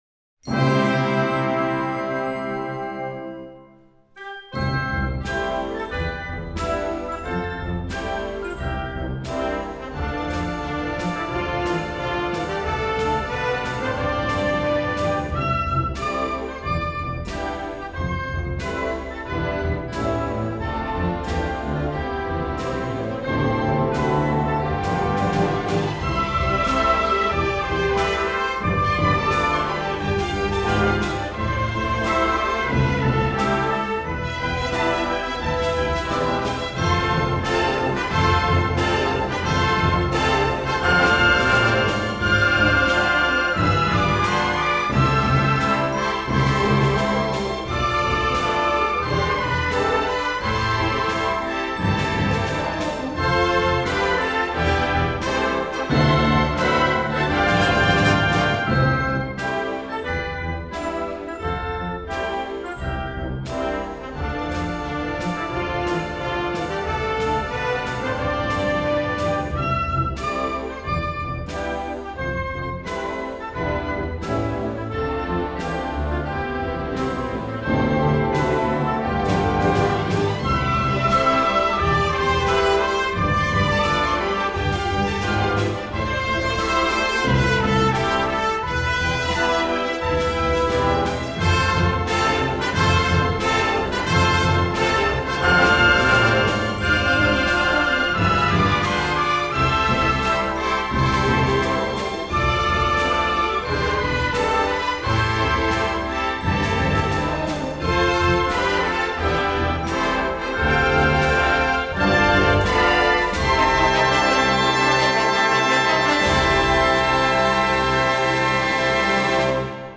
ロシアの国歌